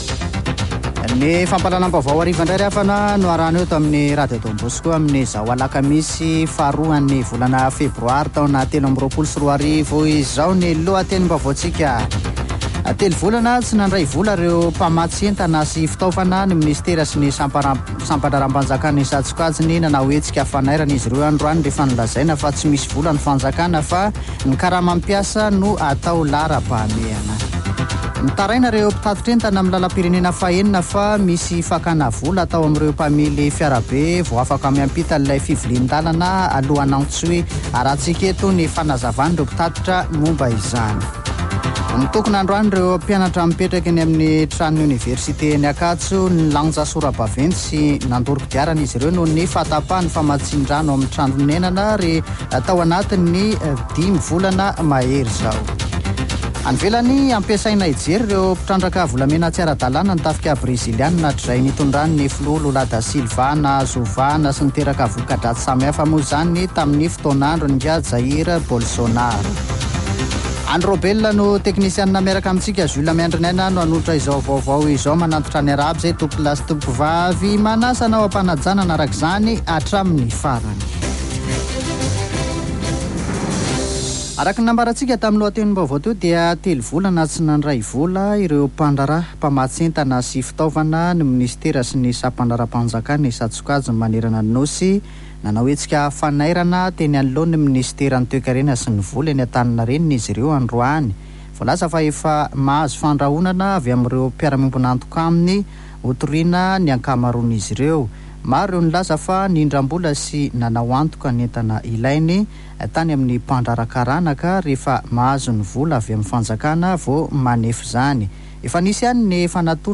[Vaovao hariva] Alakamisy 2 febroary 2023